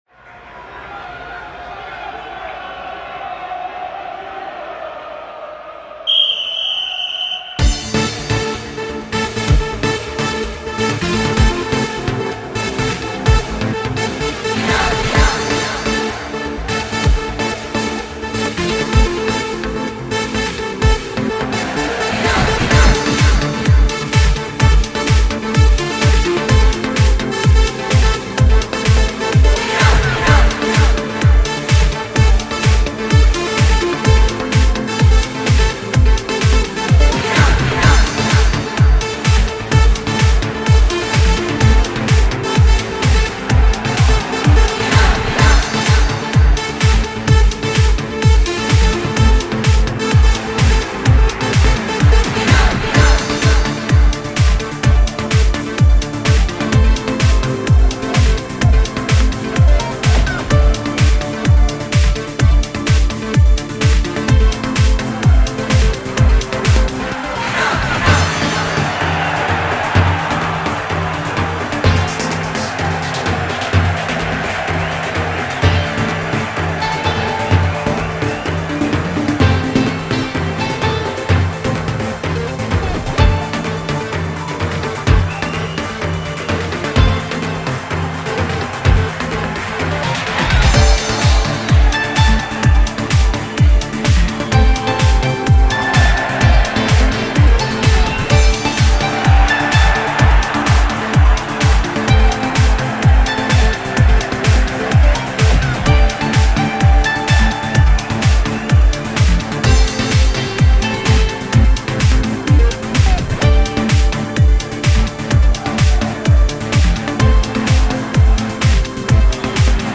an sport (energic) music-orginal mix
daw=fl 11 and just fl vsts
you can hear one of iranian instrument in this music (daf) (i used the wav sample of that)